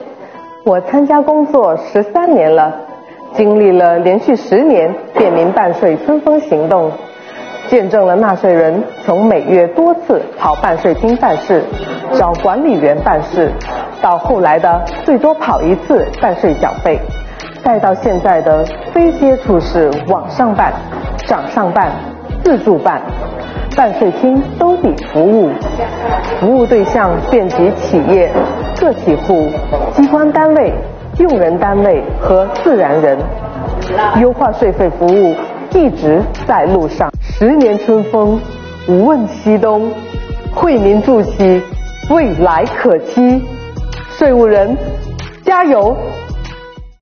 从今天起，本公众号将陆续播出“便民办税春风行动”10周年系列访谈。